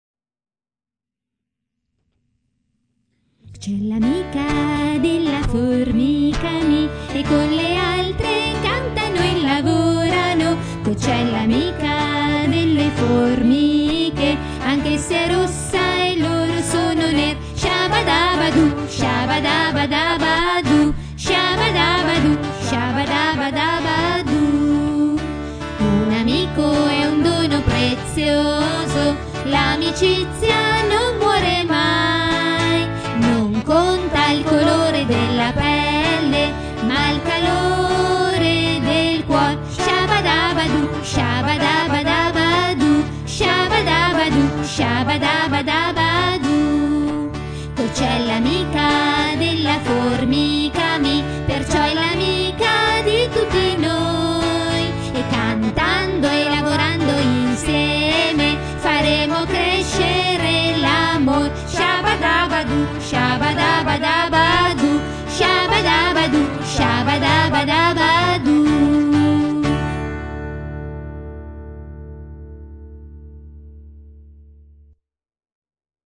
canto della Formica Mi DO FA SIb FA Cocci � l�amica della formica Mi DO e con le altre cantano e lavorano.